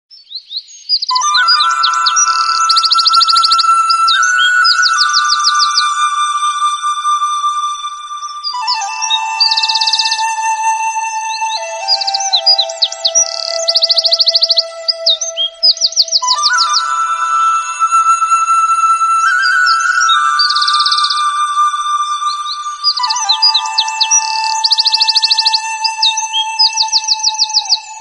Categories: Chinese